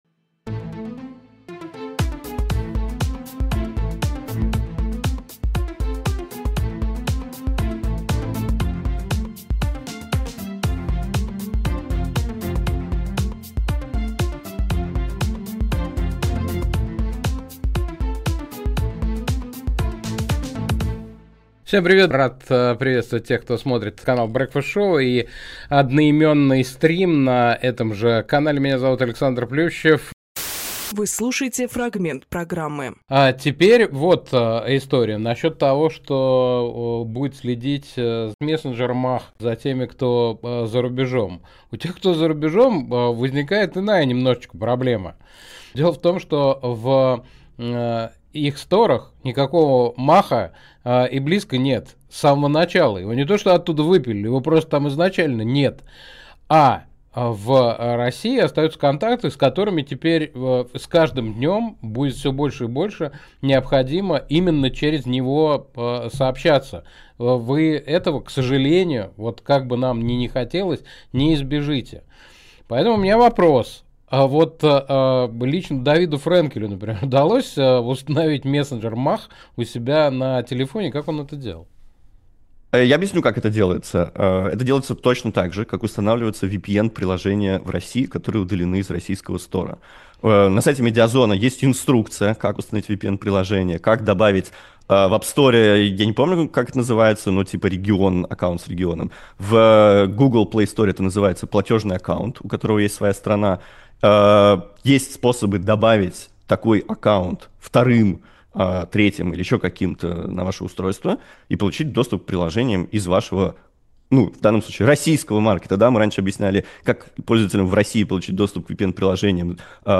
Фрагмент эфира от 26.08.25